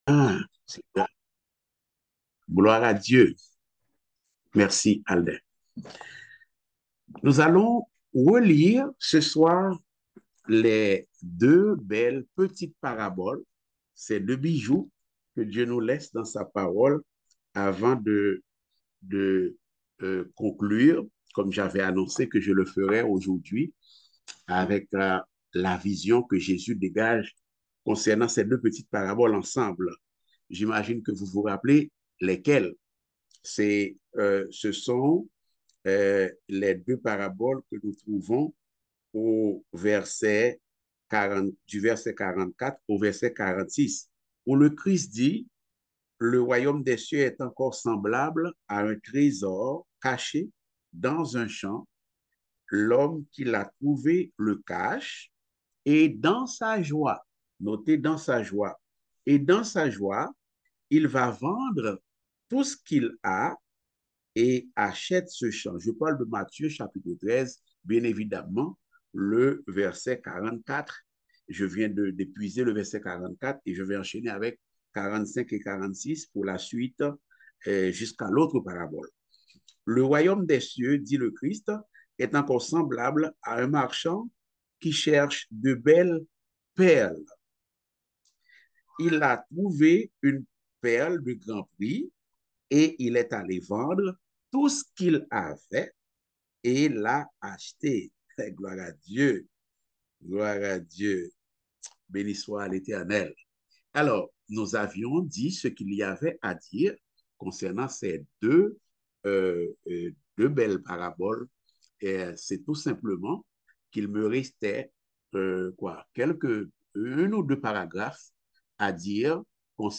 Type De Service: Études Bibliques « La vision de Jésus concernant le royaume de Dieu à travers les paraboles du levain, du trésor caché et de la perle de grand prix.